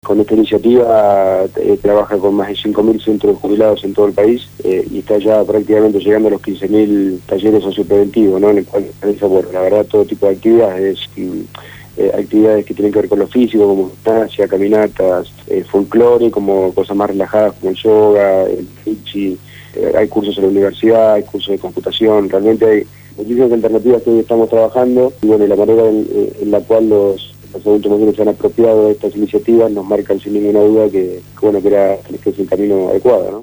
Federico Susbielles Gerente de Promoción Social y Comunitaria de la obra social de los jubilados, PAMI, fue entrevistado en el programa «Punto de partida» (Lunes a viernes de 7 a 9 de la mañana) por Radio Gráfica; en donde dio detalles del reciente convenio firmado con la secretaría de niñez, adolescencia y familia.